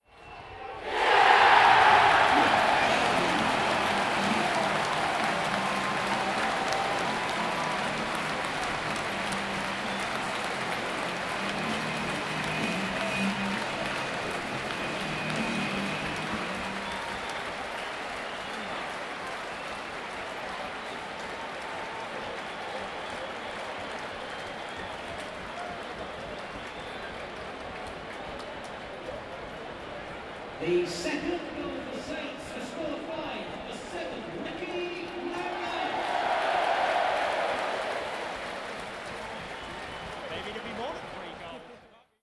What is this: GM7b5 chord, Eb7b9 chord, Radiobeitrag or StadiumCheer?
StadiumCheer